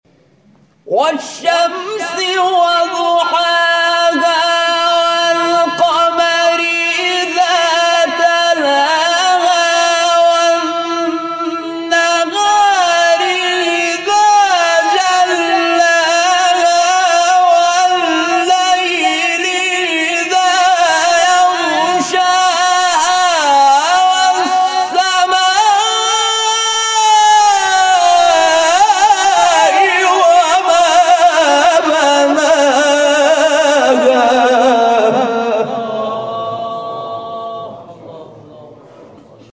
نغمات صوتی از قاریان ممتاز کشور
در مقام سه‌گاه